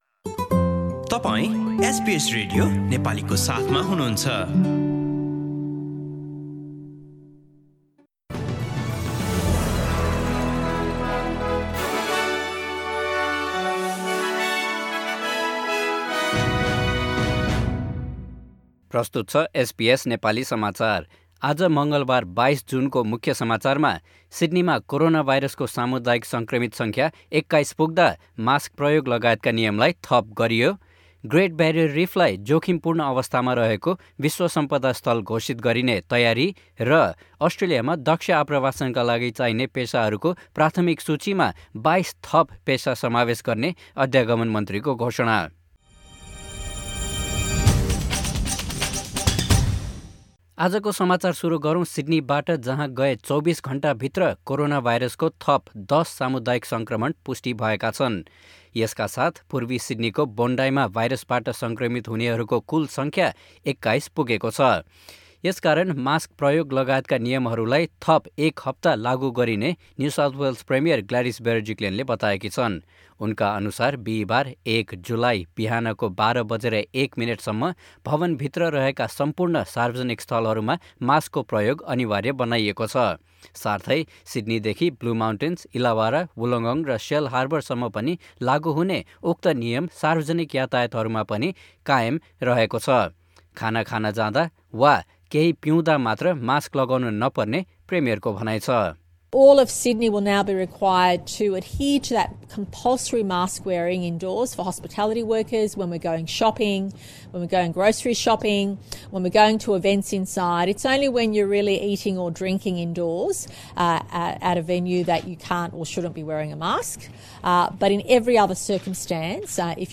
एसबीएस नेपाली अस्ट्रेलिया समाचार: मंगलबार २२ जुन २०२१